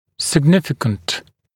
[sɪg’nɪfɪkənt][сиг’нификэнт]значительный, существенный, важный, значимый